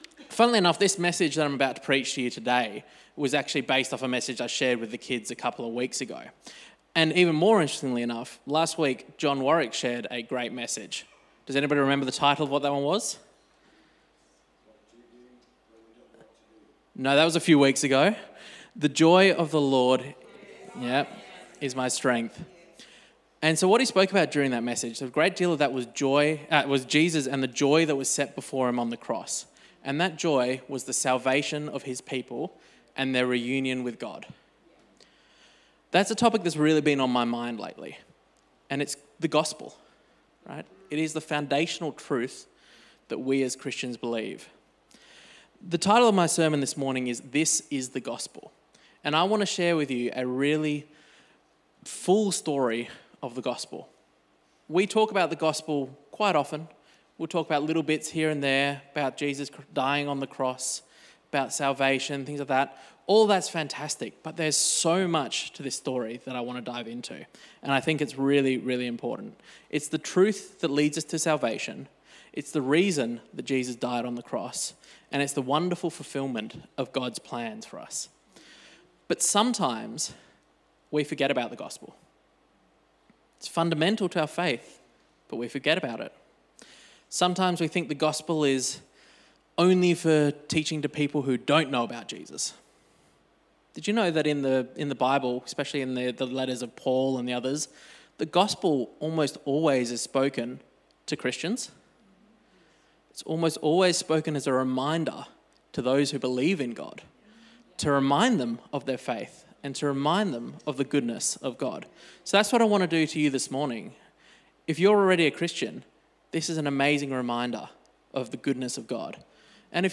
Sermon Transcript